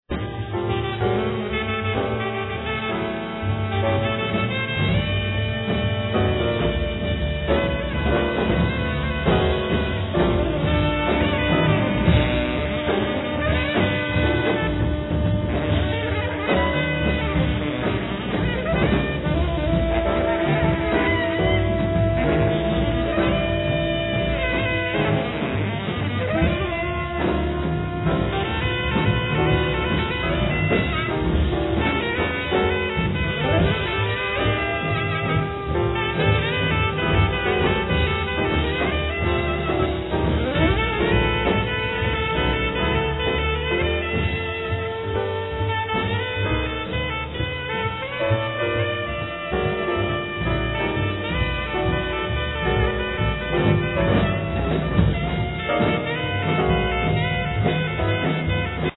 Saxes, Flutes
Clarinets
Piano, Percussion
Bass
Drums, Percussions